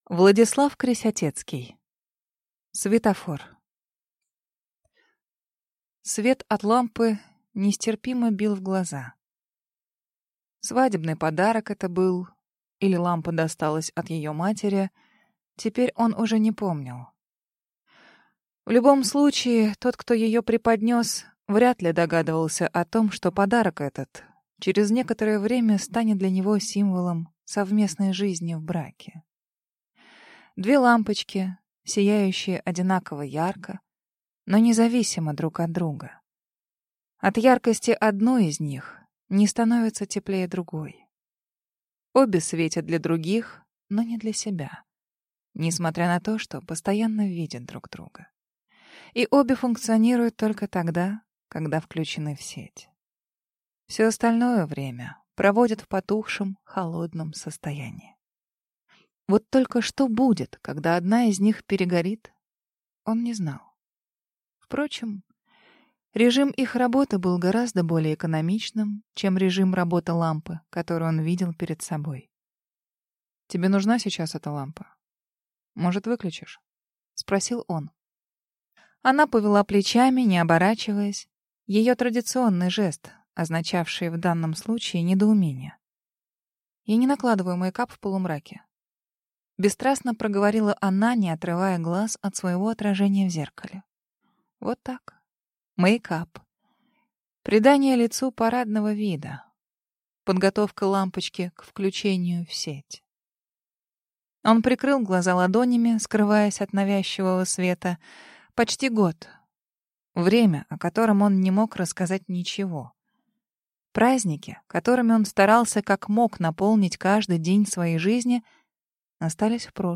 Аудиокнига Светофор | Библиотека аудиокниг